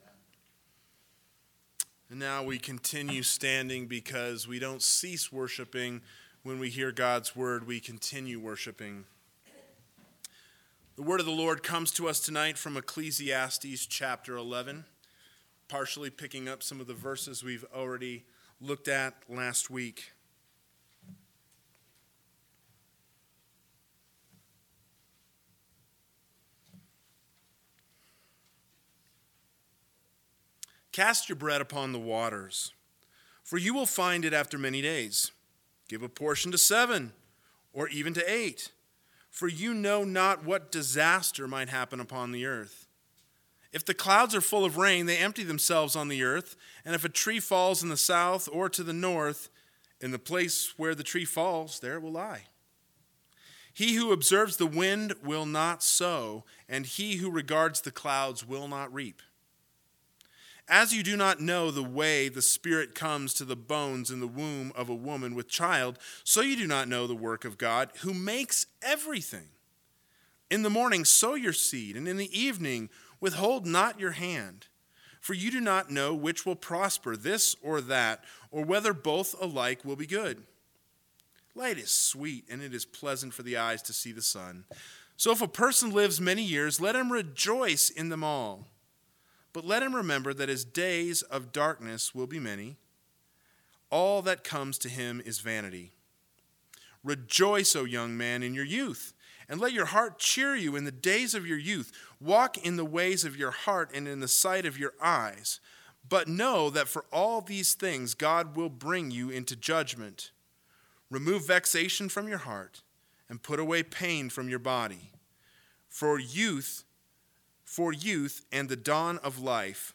PM Sermon – 10/11/2020 – Ecclesiastes 11 – God Lives, So Live With Joy!